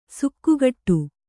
♪ sukkugaṭṭu